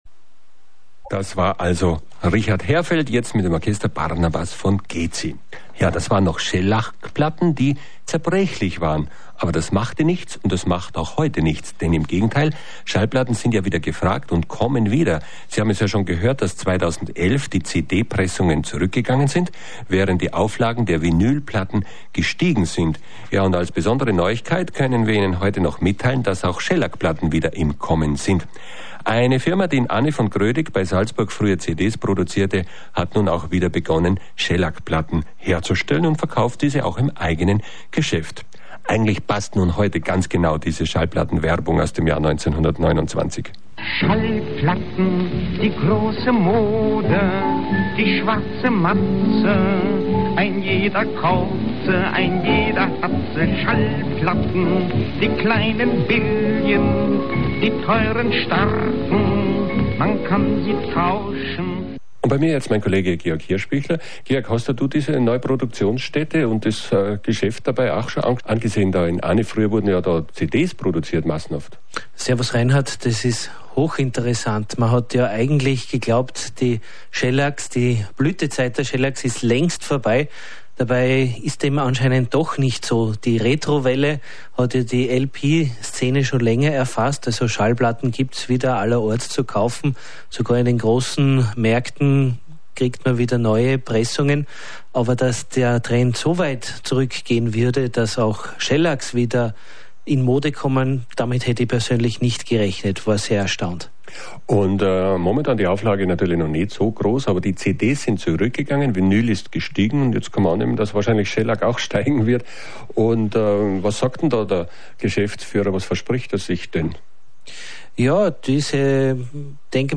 In der 365. Plattenkiste am 1. April 2012 waren folgende Interviews